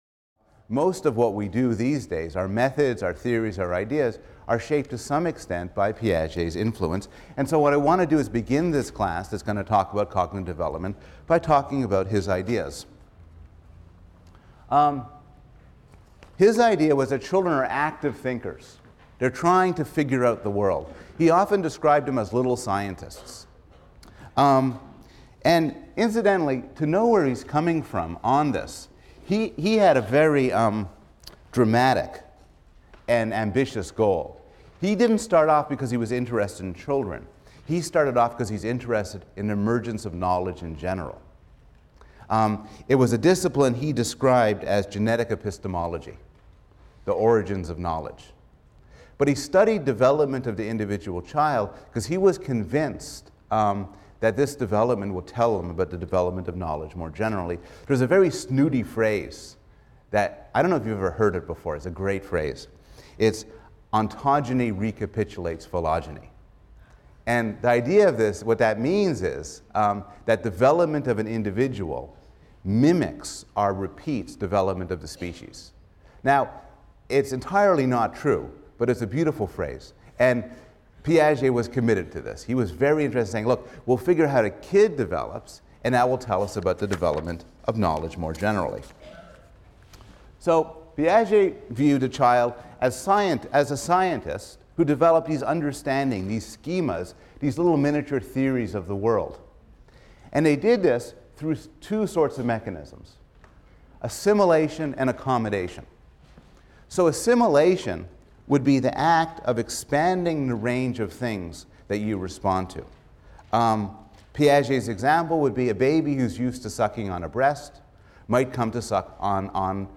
PSYC 110 - Lecture 5 - What Is It Like to Be a Baby: The Development of Thought | Open Yale Courses